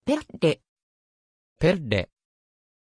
Aussprache von Pelle
pronunciation-pelle-ja.mp3